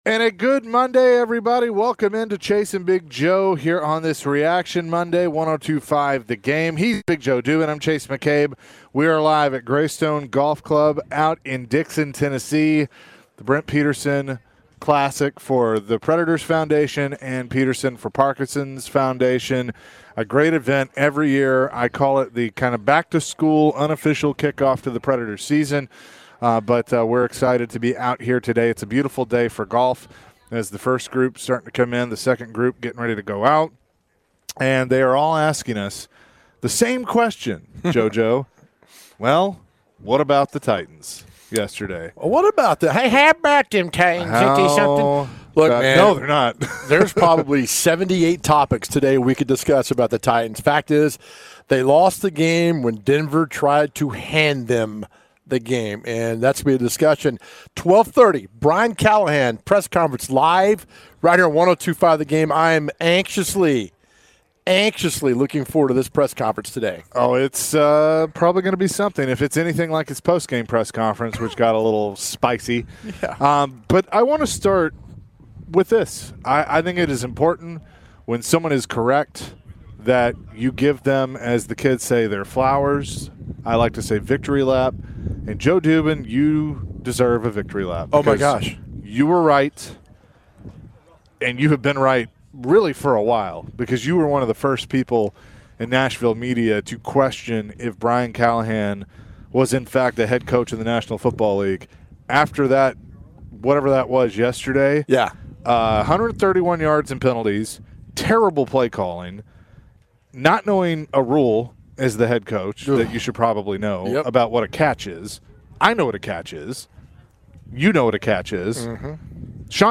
Later in the hour, Titans HC Brian Callahan spoke to media for his Monday press conference.